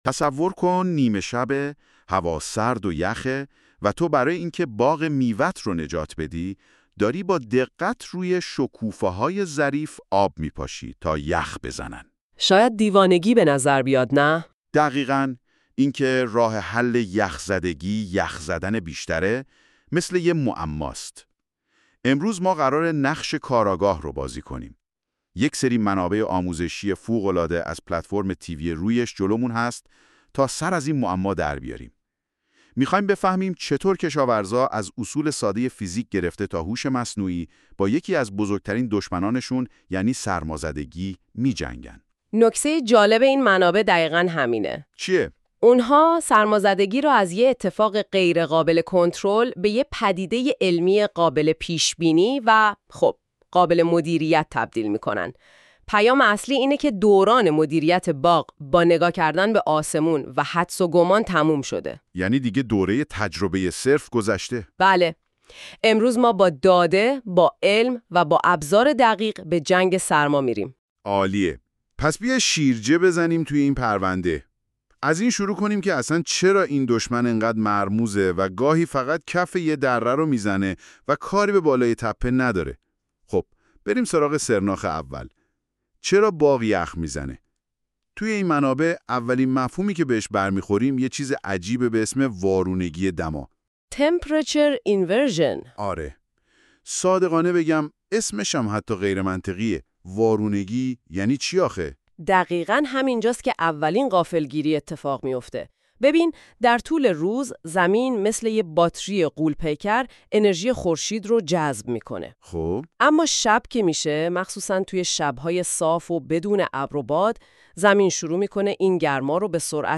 نقشه راه - نقشه ذهنی یا Mind Map -اینفوگرافی -گفتگو و تحلیل با هوش مصنوعی در کشاورزی ایران گفتگو و تحلیل با هوش مصنوعی مدیریت سرمازدگی از علم تا استراتژی میدانی اینفو گرافیک اینفو گرافیک چک‌لیست «شب یخبندان» نقشه راه